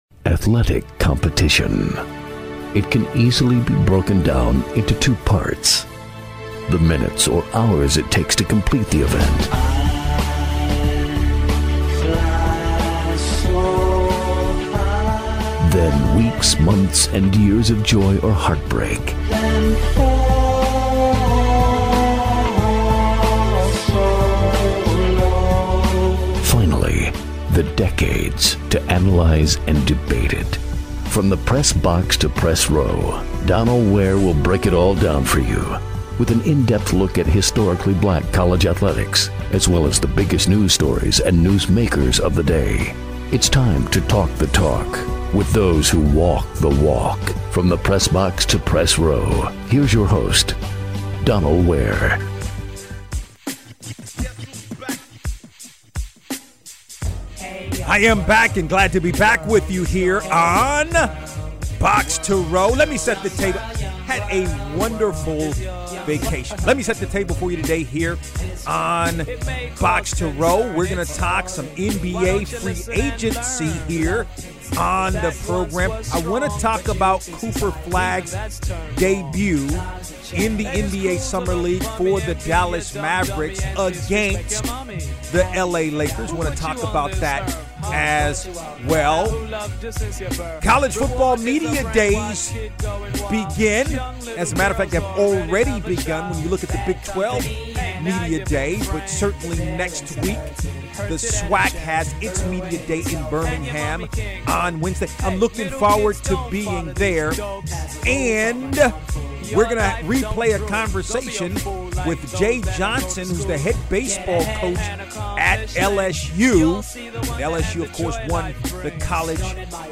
Talking with LSU head baseball coach Jay Johnson. BOXTOROW Podcast: 7/12/25
Interviews.